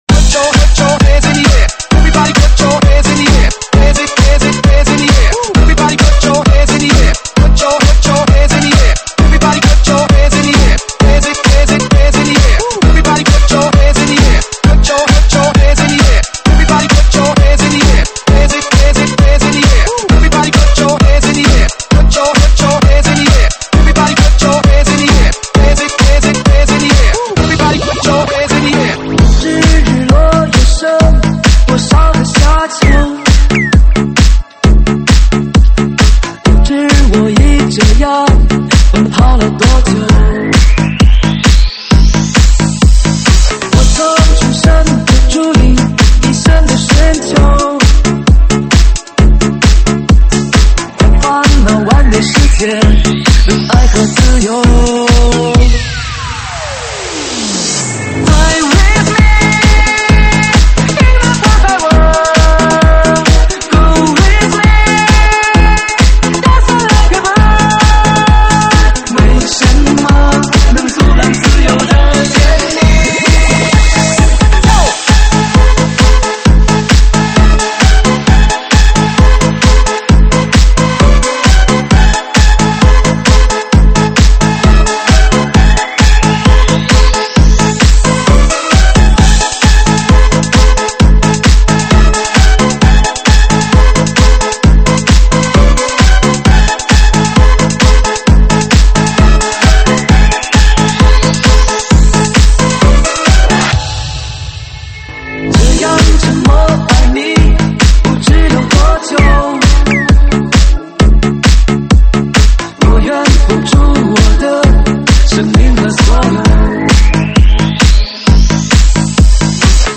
ProgHouse